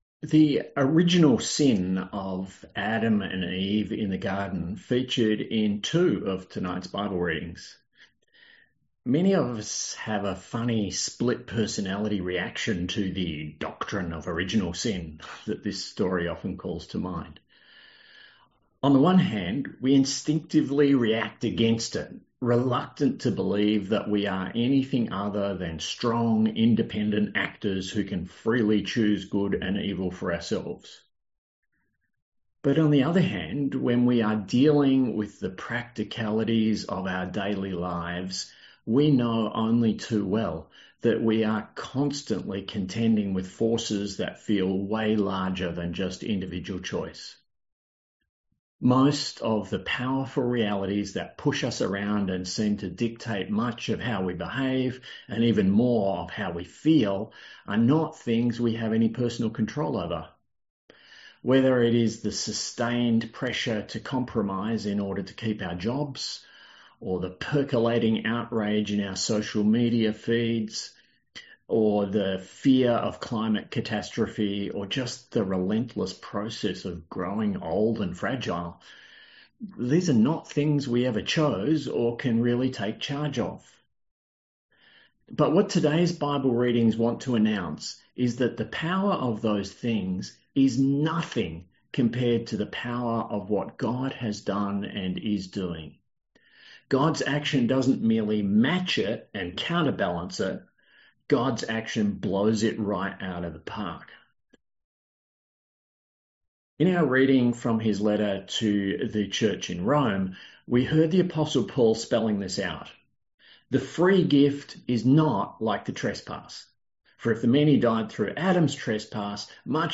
A sermon on Romans 5:12-19; Genesis 2:15-17; 3:1-7; & Matthew 4:1-11